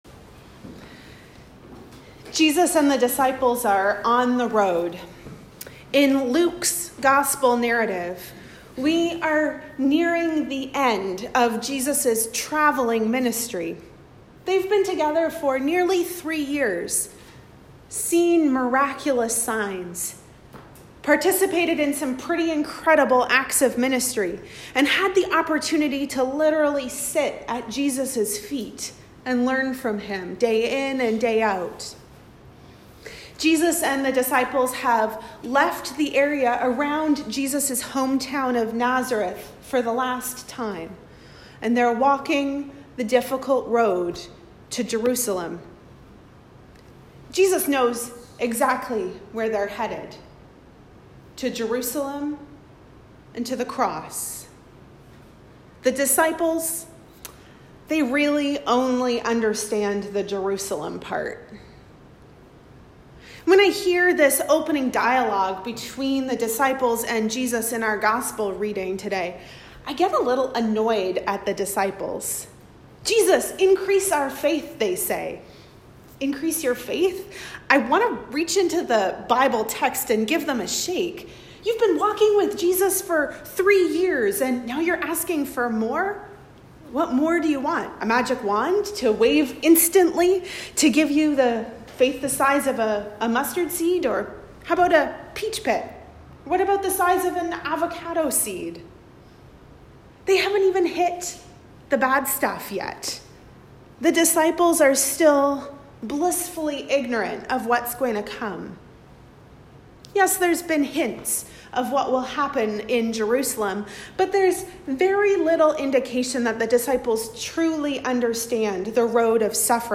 Sermons | Parish of the Valley
Recorded at St George's, South Alice